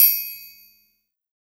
XLNT-8ty6ixed Perc - Triangle - Fefe.wav